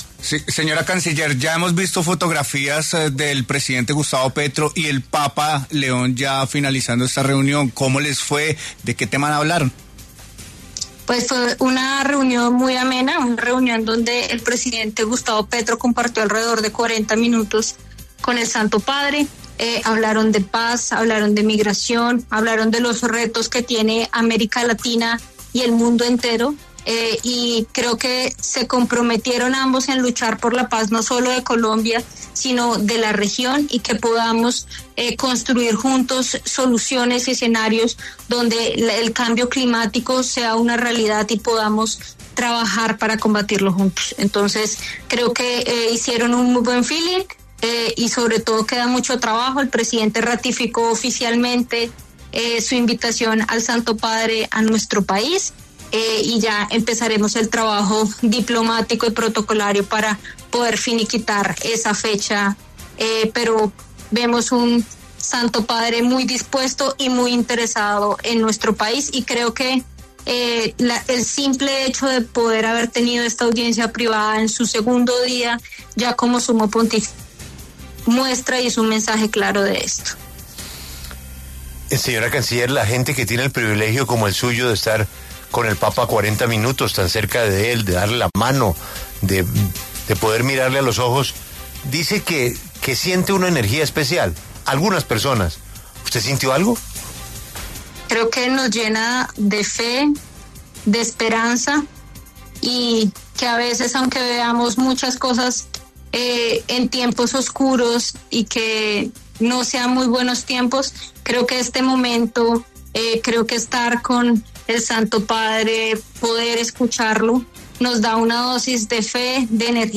En diálogo con La W, la canciller Laura Sarabia se refirió a la reunión que sostuvo el presidente Gustavo Petro, con el papa León XIV, luego del inicio de su pontificado.